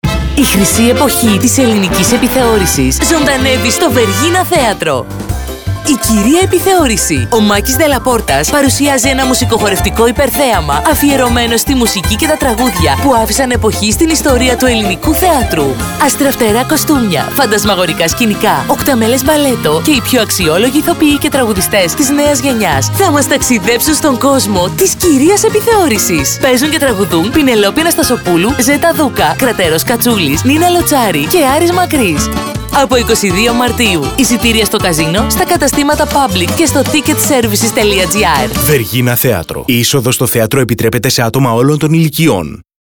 Regency Casino radio ads and tv recordings
Advertising spots for concerts and events at the Vergina Theatro, special lottery and promotional spots, delicious and special spots for Alfredos Garden and La Terrasse restaurants, and even fillers for radio stations, which we created in partnership with Orange Adv